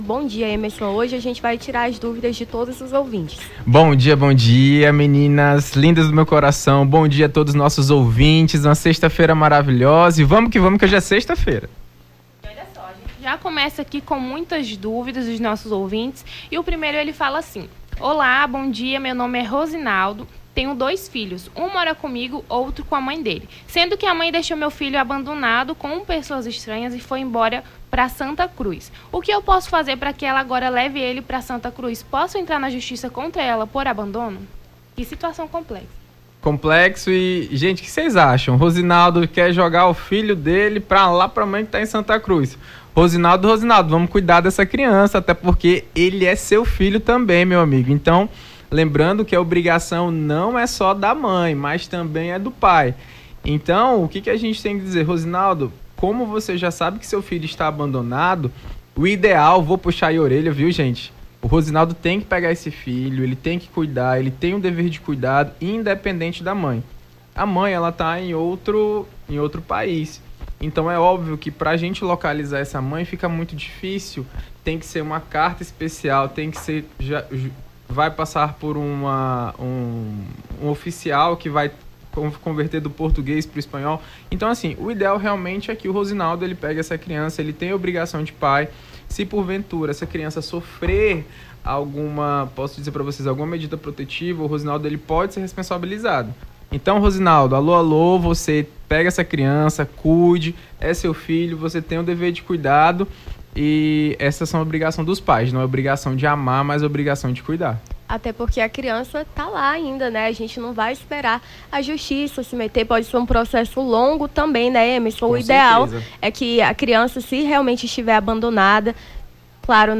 Seus direitos: advogado esclarece as dúvidas dos ouvintes sobre o direito da família